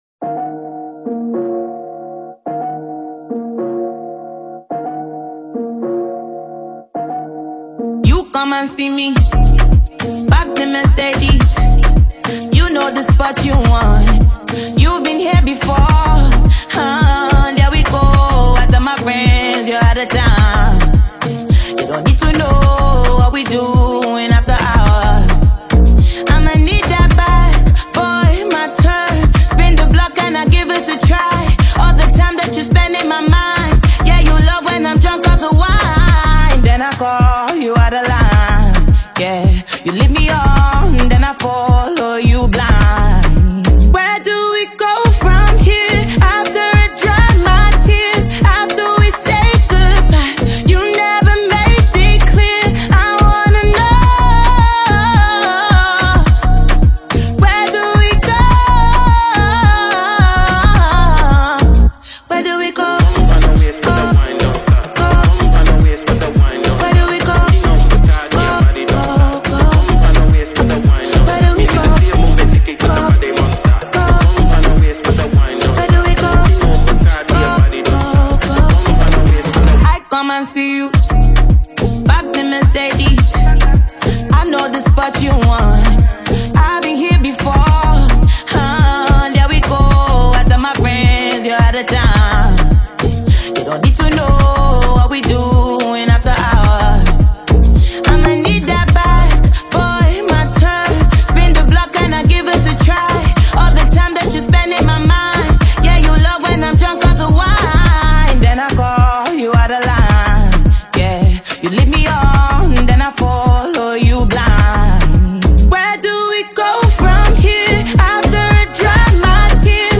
soulful Afro-Pop/R&B single
powerful vocals
modern Afro-fusion sound